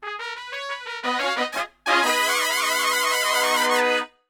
FUNK3 ABM.wav